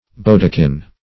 bodikin - definition of bodikin - synonyms, pronunciation, spelling from Free Dictionary
bodikin \bod"i*kin\, n.